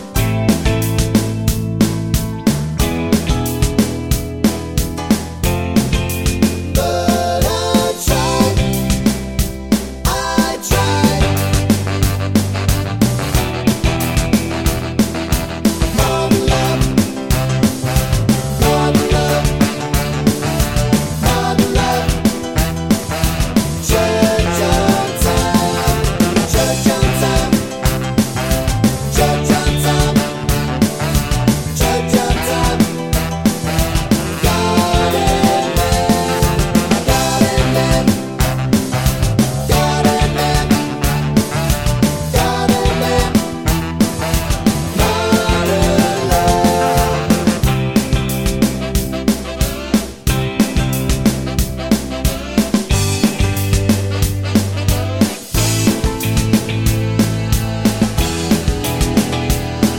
No Sax Solo With Backing Vocals Pop (1980s) 3:53 Buy £1.50